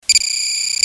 scanning